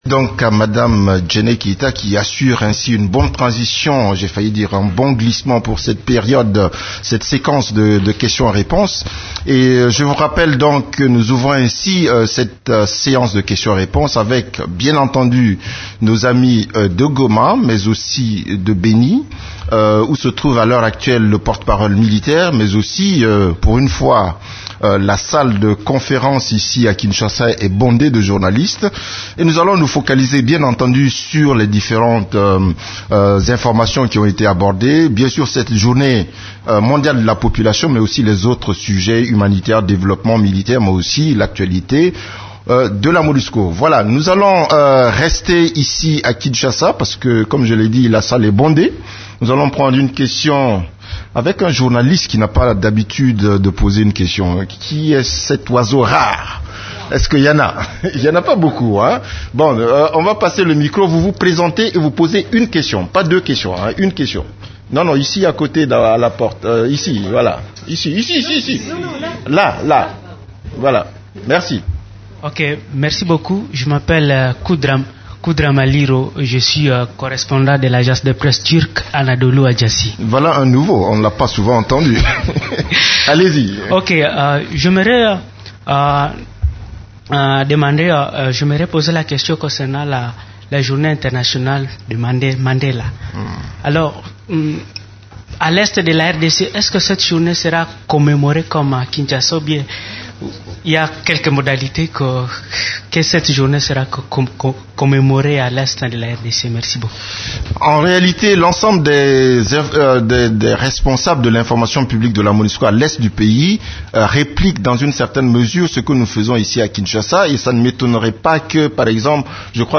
Conférence de presse du 13 juillet 2016
La conférence de presse hebdomadaire des Nations unies du mercredi 13 juillet à Kinshasa a porté sur les activités des composantes de la MONUSCO, les activités de l’équipe-pays et la situation militaire.